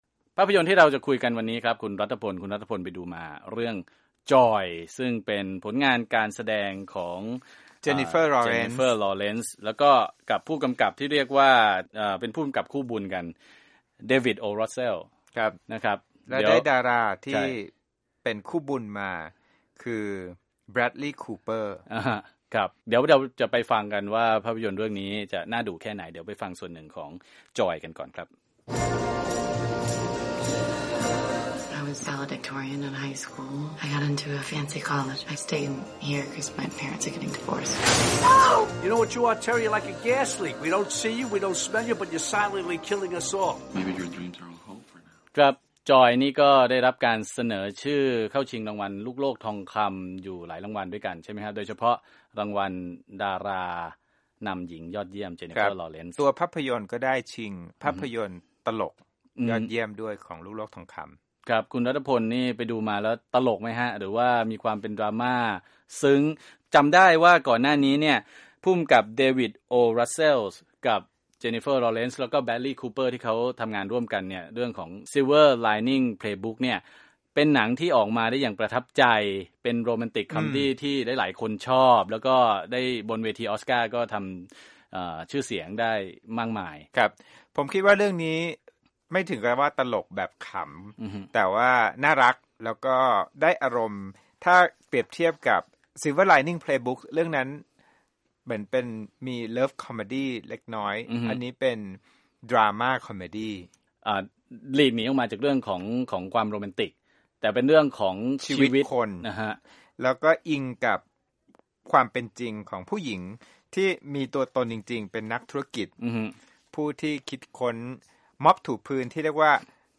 คุยหนัง Joy